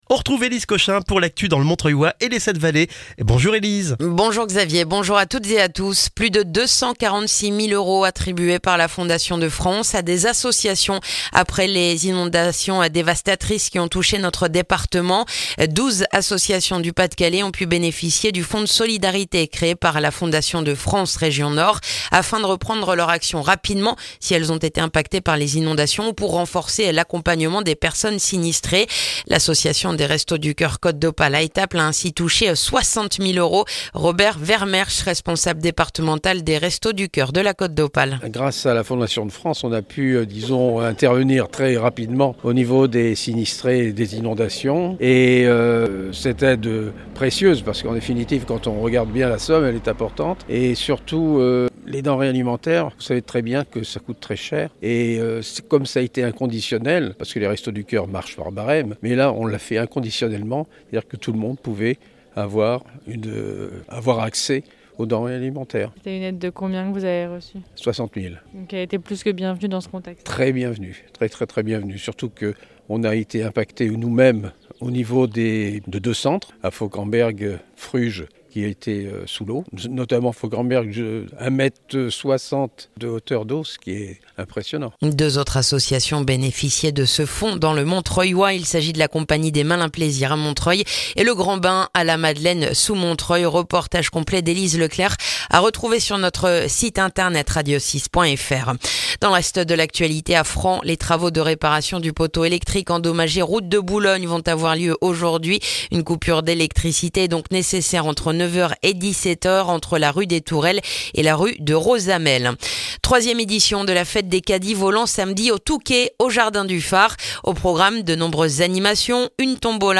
Le journal du jeudi 13 juin dans le montreuillois